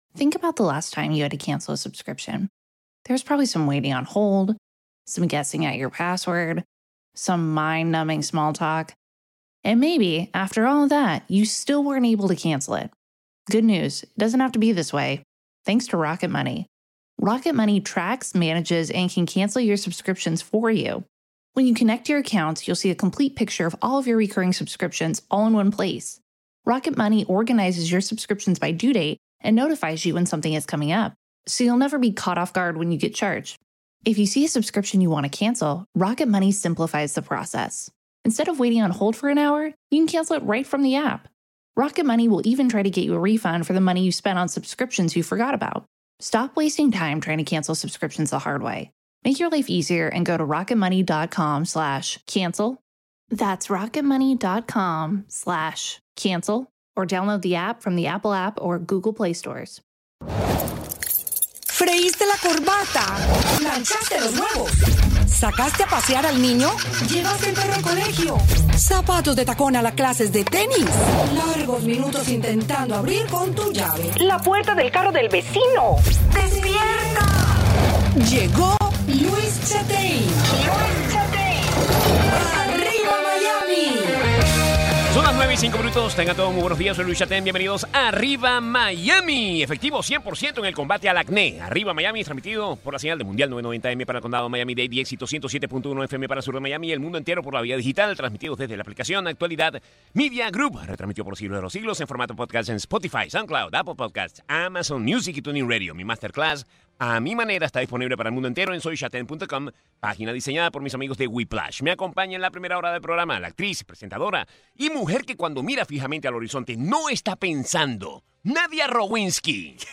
Conversa con el actor Alejandro Nones sobre la serie "Quién mató a Sara" de Netflix.